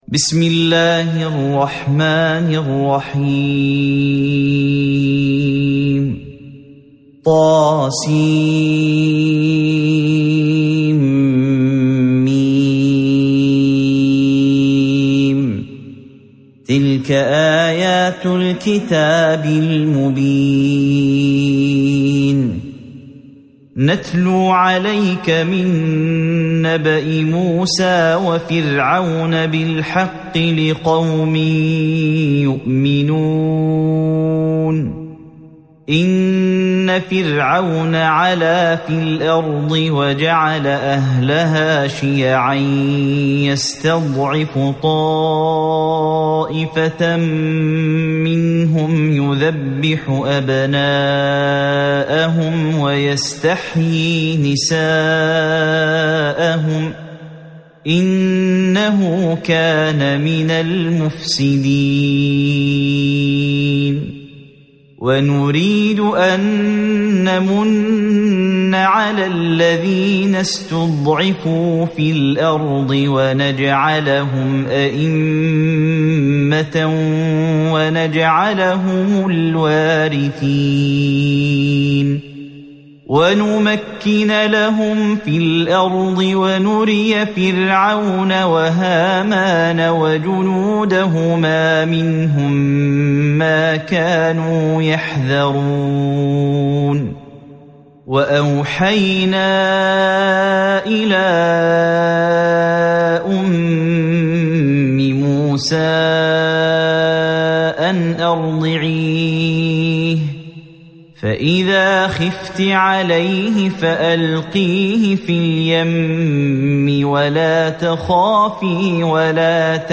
سورة القصص مكية عدد الآيات:88 مكتوبة بخط عثماني كبير واضح من المصحف الشريف مع التفسير والتلاوة بصوت مشاهير القراء من موقع القرآن الكريم إسلام أون لاين